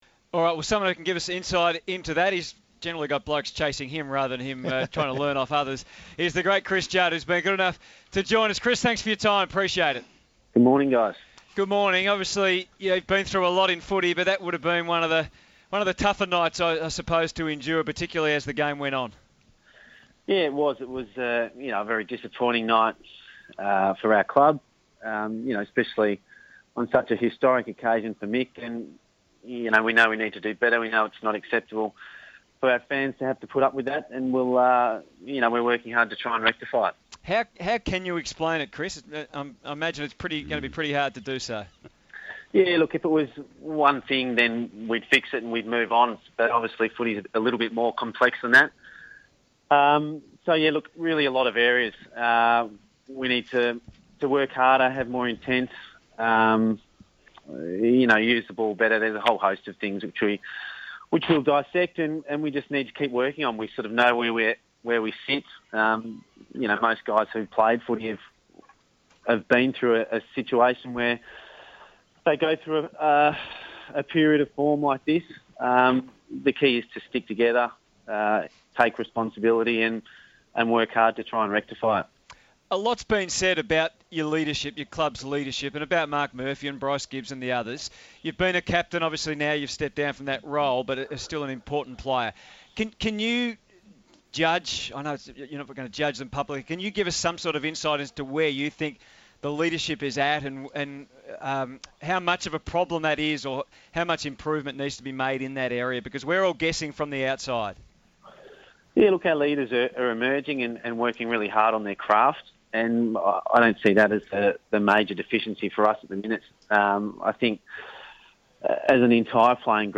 Super star Chris Judd joined the team for a very open and frank discussion about the Blues following their loss last night.